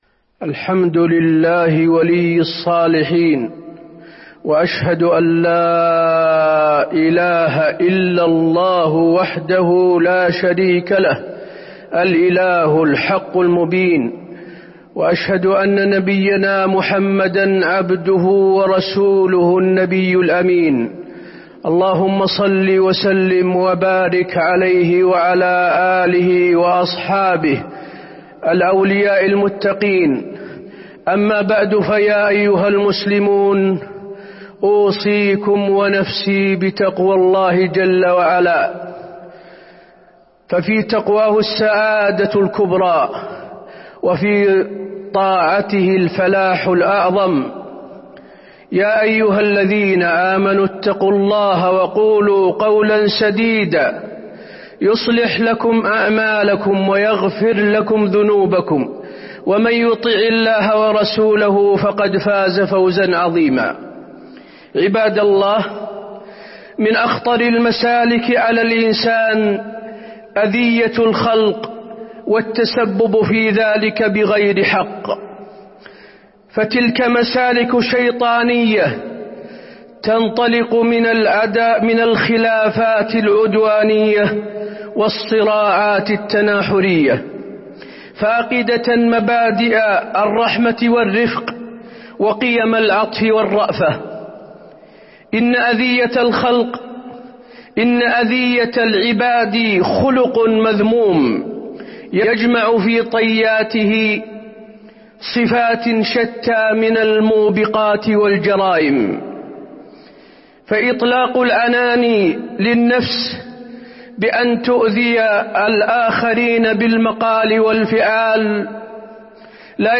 تاريخ النشر ٢٦ ربيع الثاني ١٤٤٢ هـ المكان: المسجد النبوي الشيخ: فضيلة الشيخ د. حسين بن عبدالعزيز آل الشيخ فضيلة الشيخ د. حسين بن عبدالعزيز آل الشيخ كف الأذى